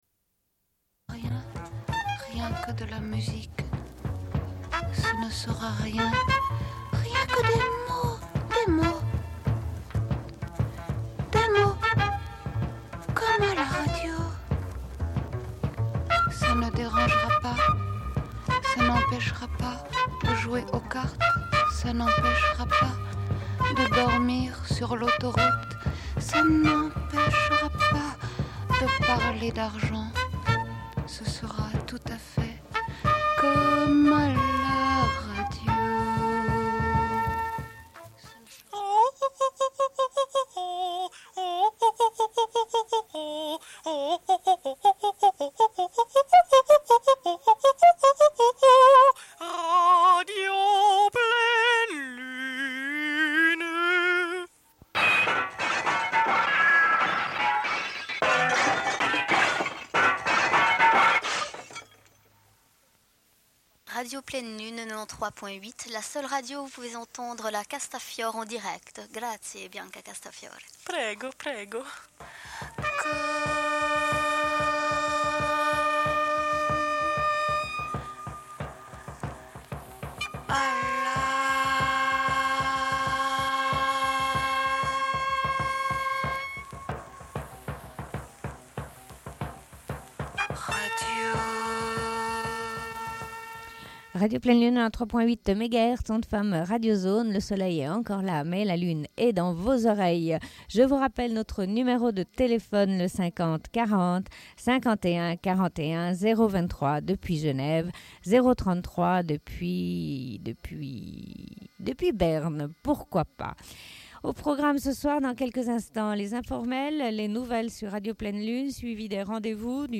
Bulletin d'information de Radio Pleine Lune du 04.05.1994 - Archives contestataires
Une cassette audio, face B30:50